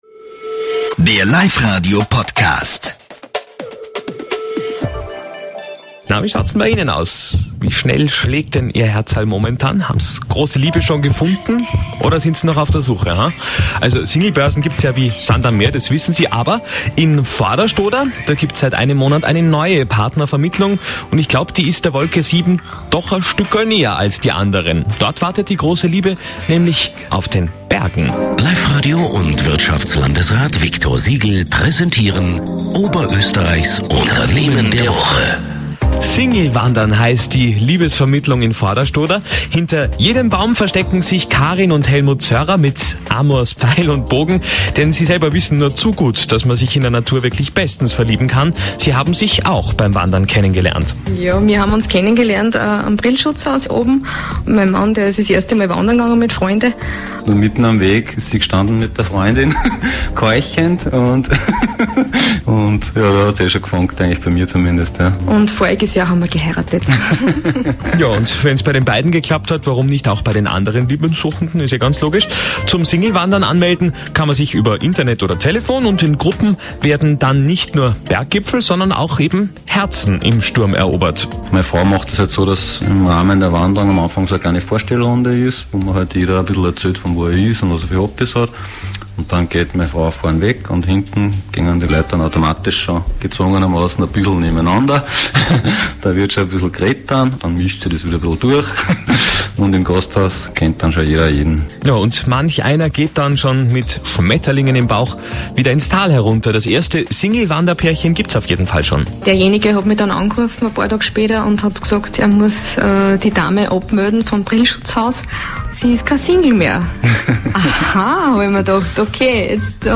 Life Radio Interview